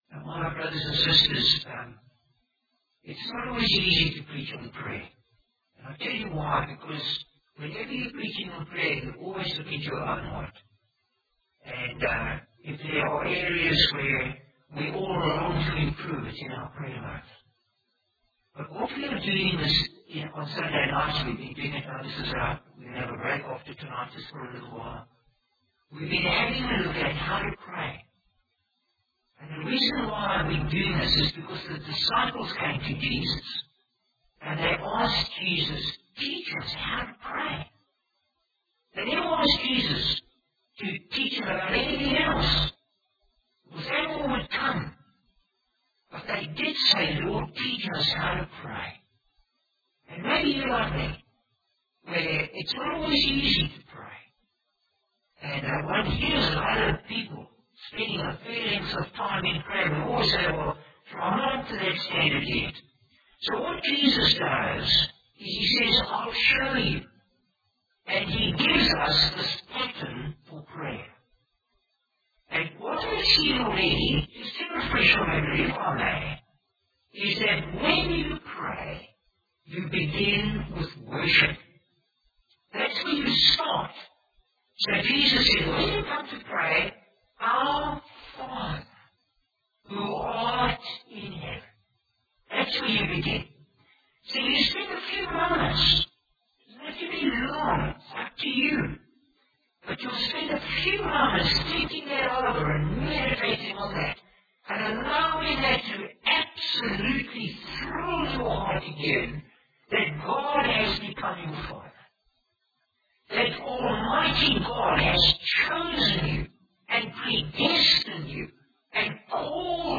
Bible Text: Matthew 6:10 | Preacher: Bishop Warwick Cole-Edwards | Series: Lord Teach us to Pray